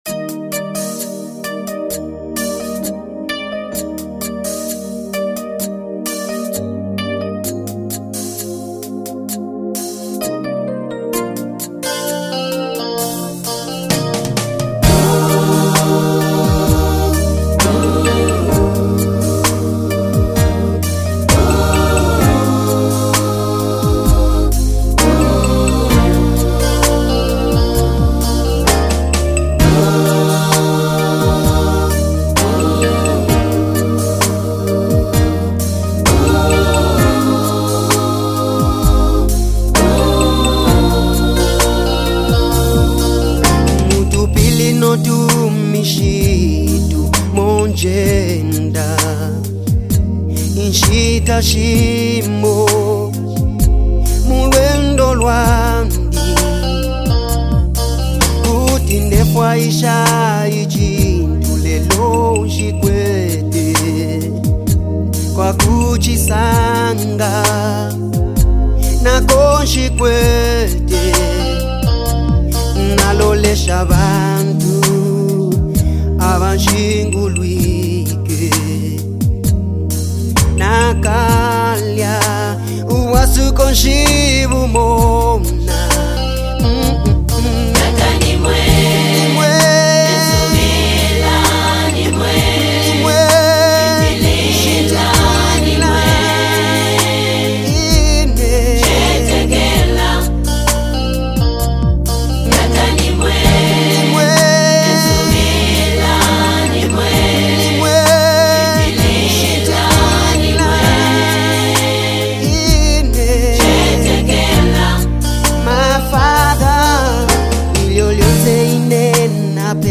Zambia’s award-winning gospel minister
heartfelt vocals and expressive style